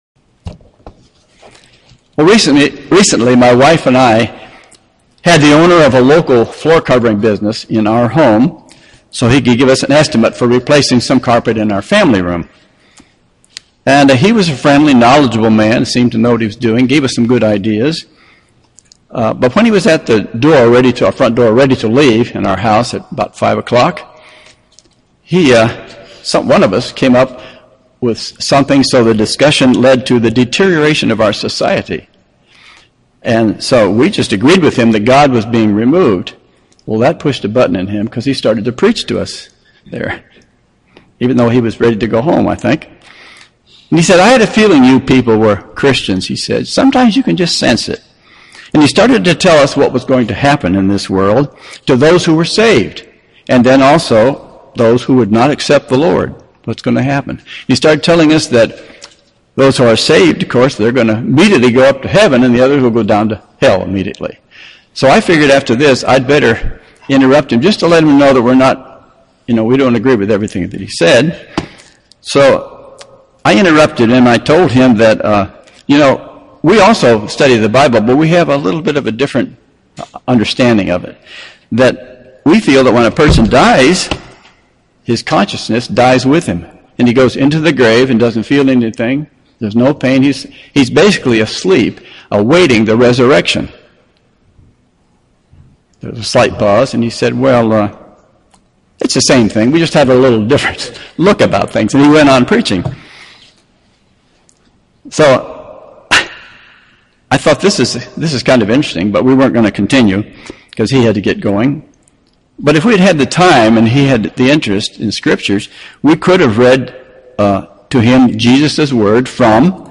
UCG Sermon Studying the bible?
Given in Buford, GA